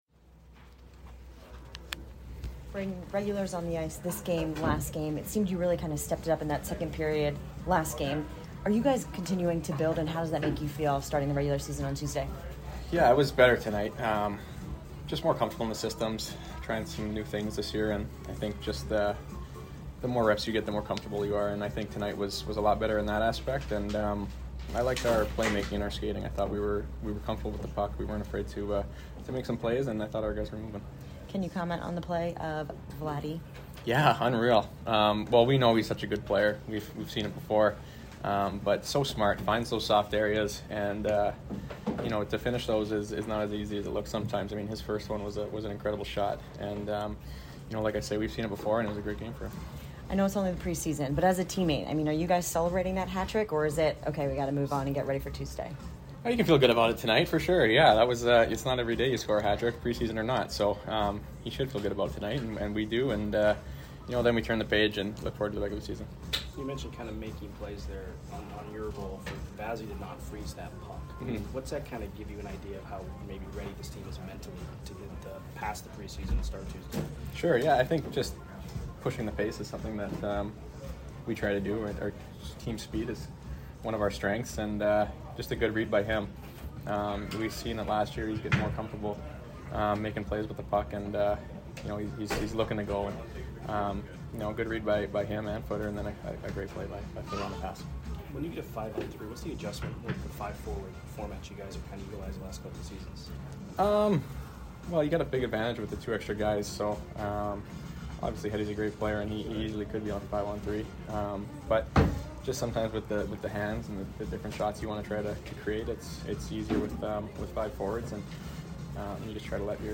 Brayden Point Postgame 10/8/22 vs. FLA